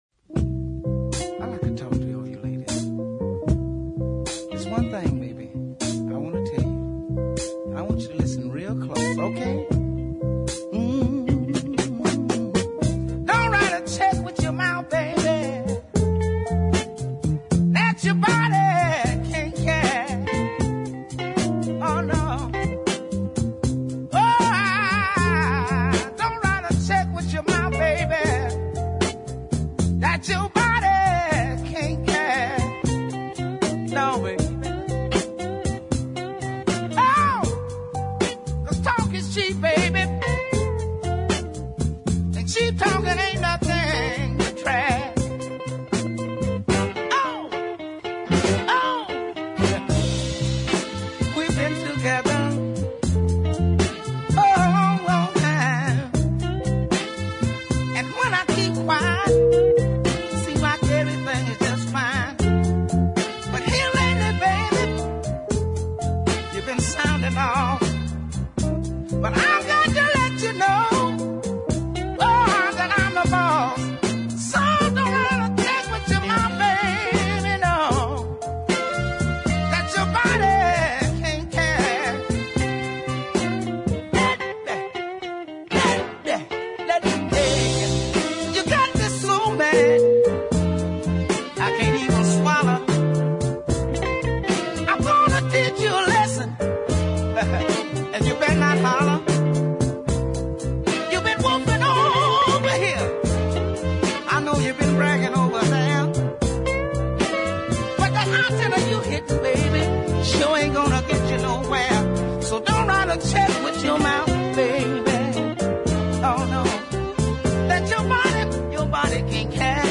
West Coast soul/blues style
but that’s no bad thing as it’s so catchy